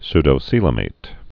(sdō-sēlə-māt)